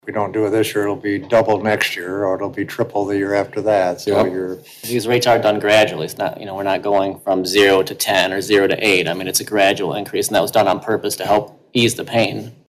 Mayor Tom Kramer and Council member Travis Machan pointed out this was part of a gradual rate increase.